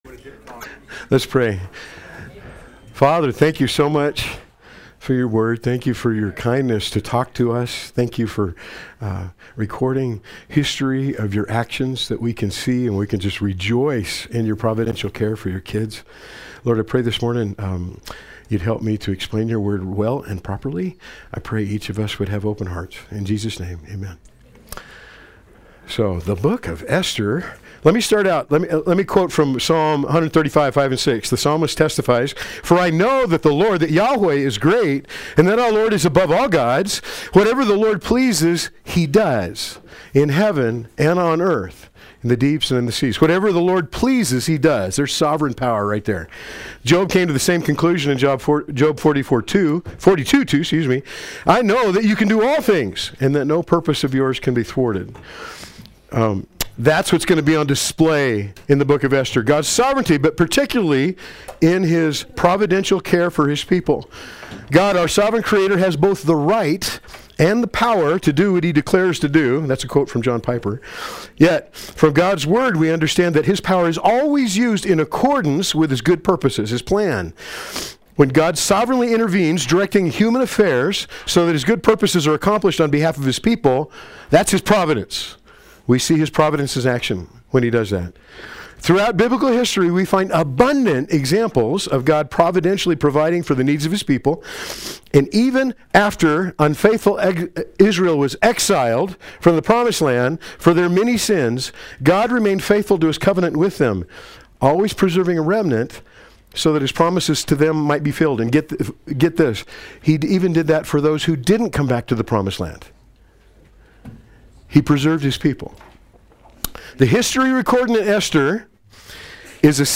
Play Sermon Get HCF Teaching Automatically.
Esther Adult Sunday School